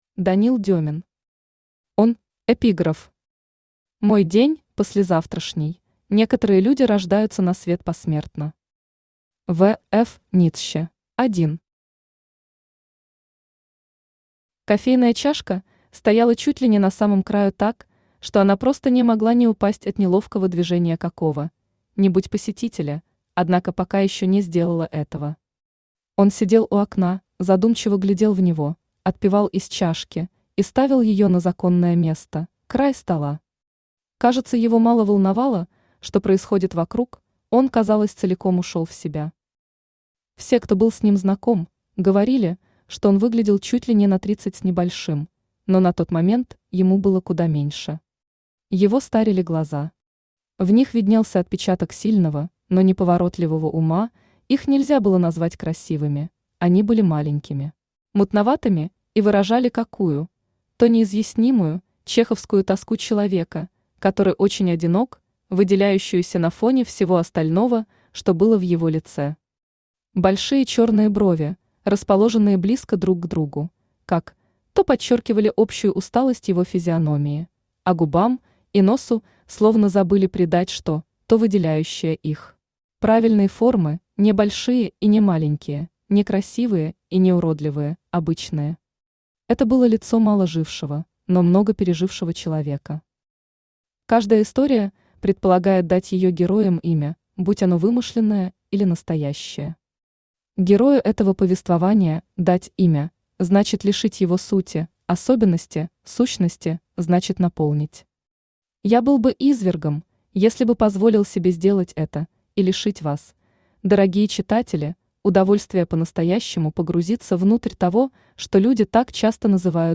Aудиокнига Он Автор Данил Дёмин Читает аудиокнигу Авточтец ЛитРес.